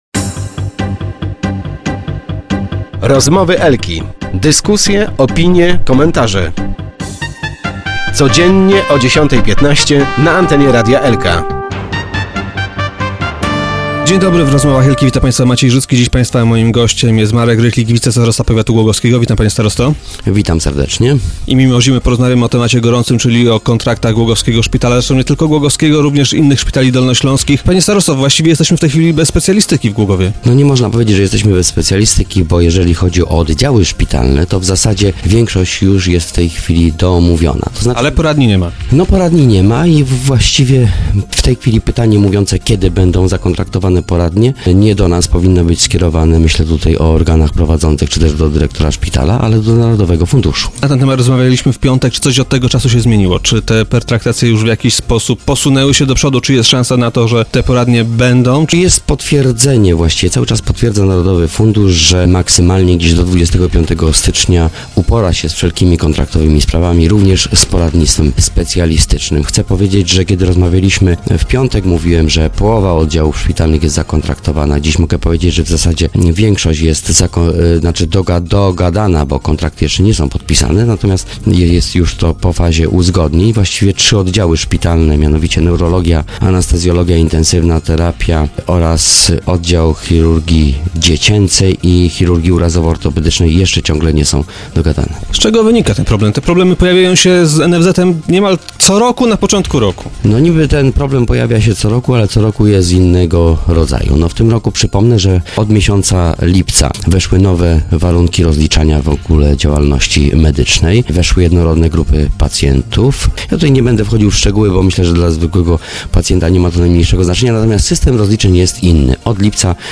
Ciągle trwają negocjacje pomiędzy głogowskim ZOZ-em, a Narodowym Funduszem Zdrowia. - Mamy nadzieję, że ten problem zostanie rozwiązany do końca stycznia - twierdzi Marek Rychlik wicestarosta głogowski, który był dziś gościem Rozmów Elki.